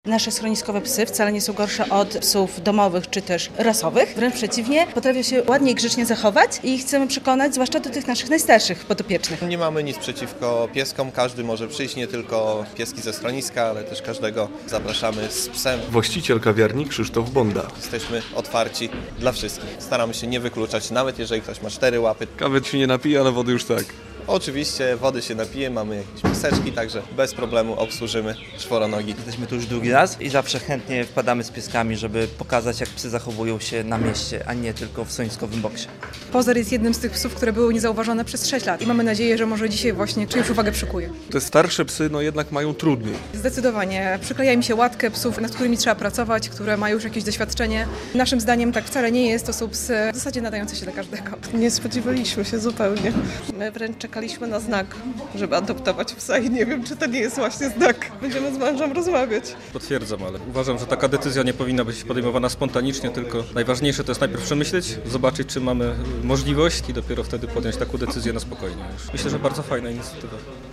Radio Białystok | Wiadomości | Wiadomości - Białostockie schronisko dla zwierząt zachęca do adopcji starszych psów